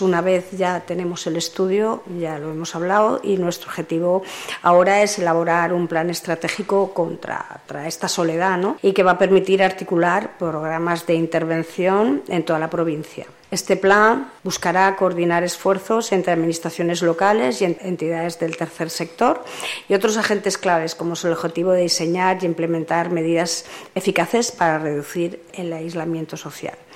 Corte-Diputada-soledad.mp3